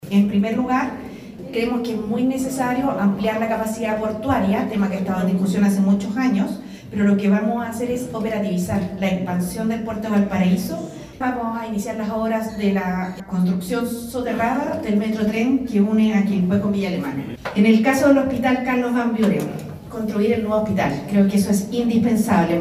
La más importante tuvo lugar en el sector de Gómez Carreño, donde la aspirante a La Moneda, se reunió con vecinos.
Dentro de los compromisos que más destacan, está la ampliación portuaria, el soterramiento del metrotren Quilpué – Villa Alemana y la construcción del nuevo Hospital Carlos Van Buren. Así lo dijo Jara.